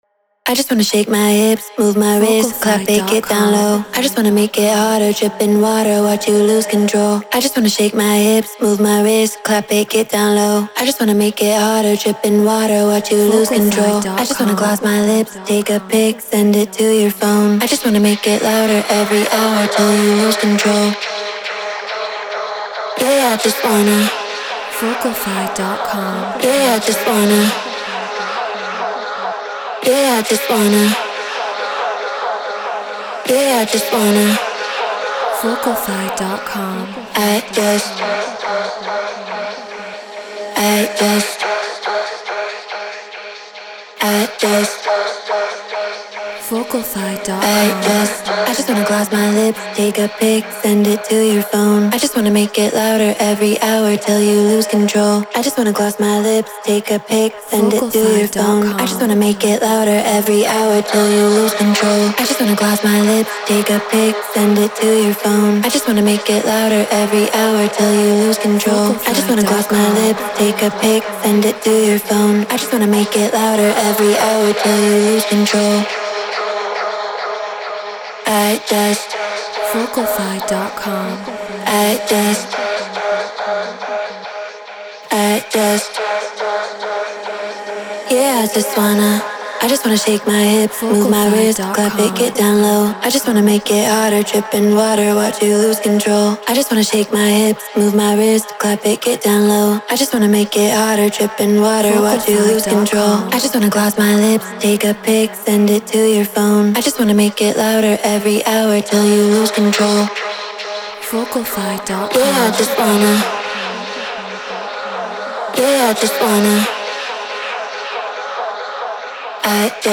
Techno 140 BPM C#min
Shure SM7B Scarlett 2i2 4th Gen Ableton Live Treated Room